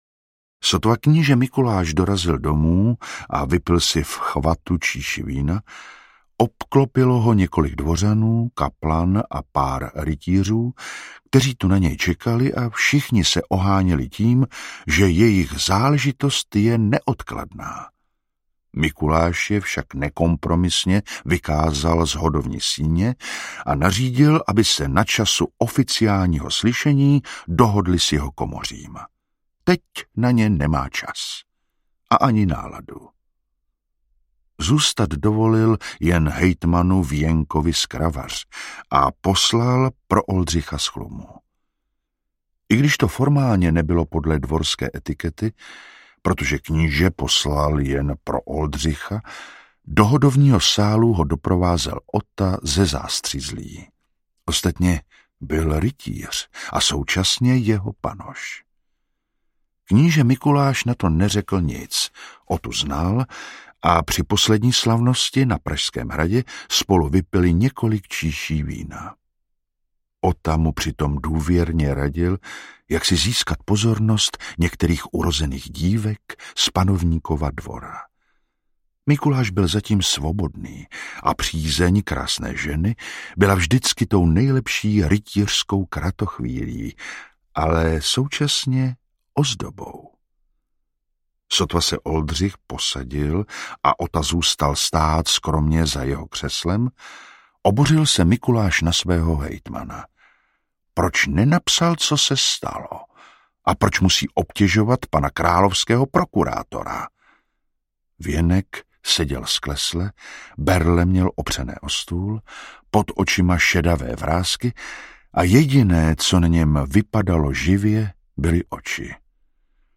Krvavé slzy audiokniha
Ukázka z knihy